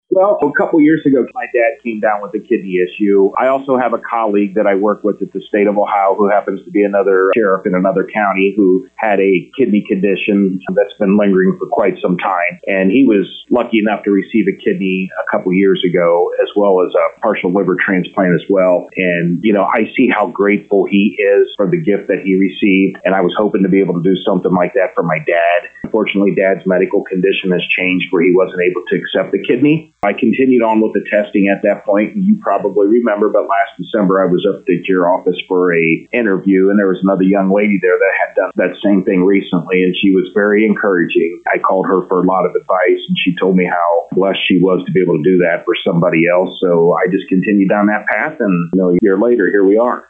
To hear comments with Sheriff Timmerman: